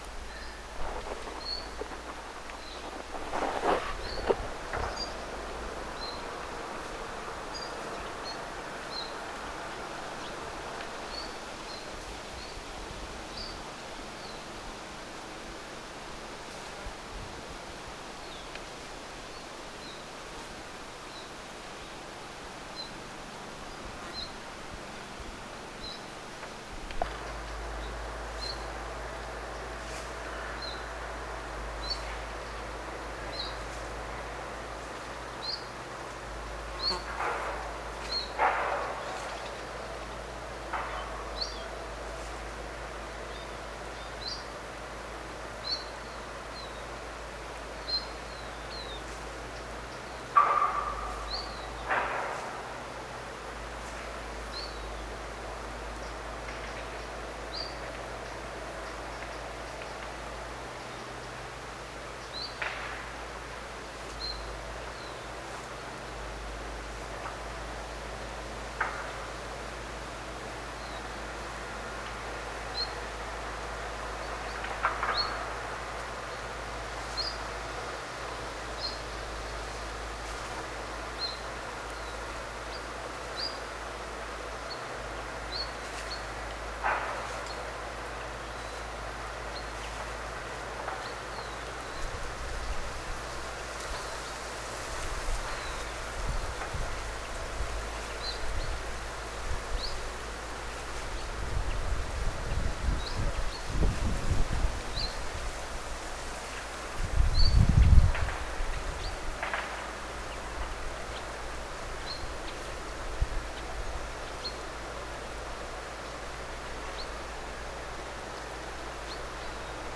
tässäkin alussa hieman sen ääntelyä
Myös bizt-tiltaltin ääniä, jotain lepertelyä.
Vihervarpunen.
alussa_ilmeinen_idantiltaltti_lisaa_aantelya_hiukan_sitten_ilmeisesti_bizt-tiltaltti_ym_vihervarpunen.mp3